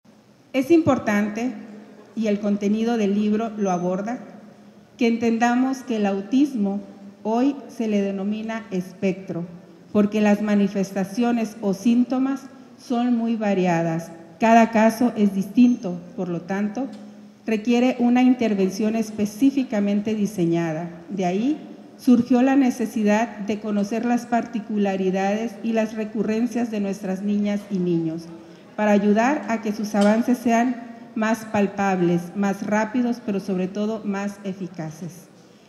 CITA-1-AUDIO-PRESIDENTA-DIF-SINALOA-PRESENTACION-DEL-LIBRO-AUTISMO-DE-LA-BRUMA-A-LA-ESPERANZA-.mp3